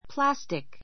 plǽstik